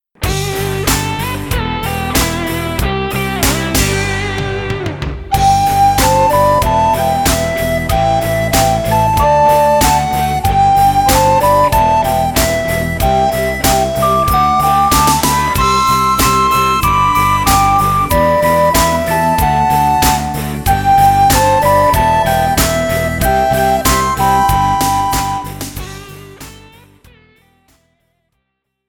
Besetzung: 1-2 Sopranblockflöten